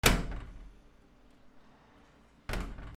扉
/ K｜フォーリー(開閉) / K05 ｜ドア(扉)